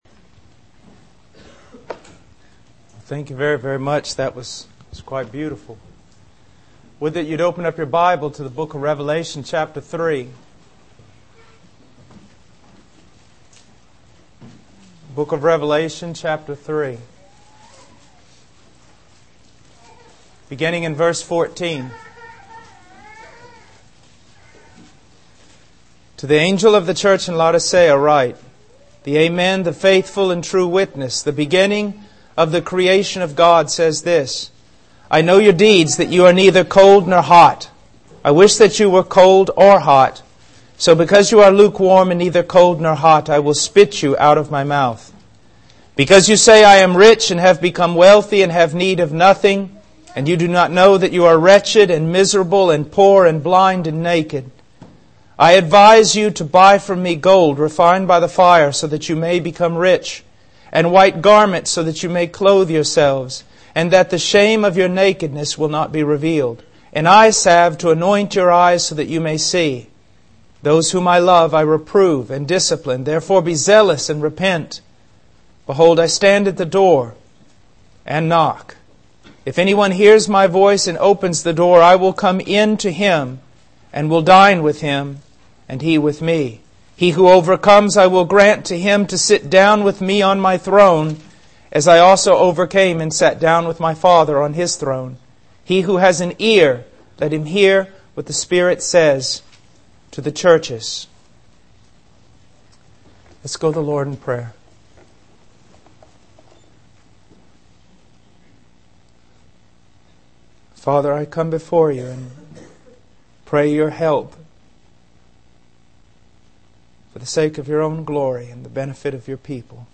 In this sermon, the preacher emphasizes the importance of living a purposeful life. He challenges the audience to consider what they want to live for and encourages them to serve the Lord and their generation.